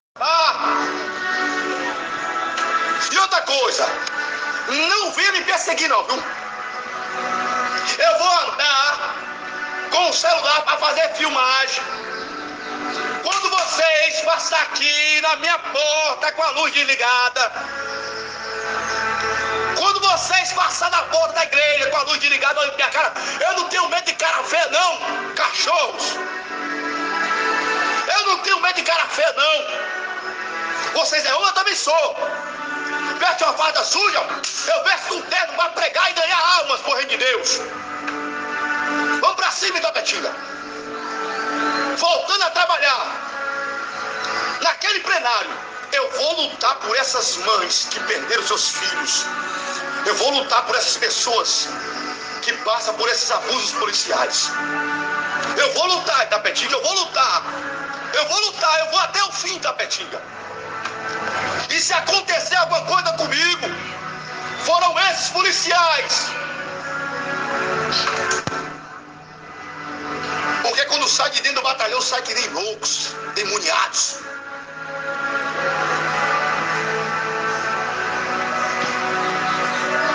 Contudo o efuxico conseguiu áudios e vídeos onde os dois aparecem virados no cão, o Assessor tirado a gravata todo “imbunecado” e o pastor tirado a pombo sujo, “com boné aba reta”, nas imagens e áudios obtidos nas redes sociais. eles aparecem enchendo o cu de coragem e proferindo ameaças e xingamentos contras as “AUTORIDADES MILITARES” e chegaram a chamar o deputado estadual Marcos Prisco de Acabado e satanás.
Audio-Pastor-e-Vereador-Chamando-os-Militares-de-Cachorros.mp3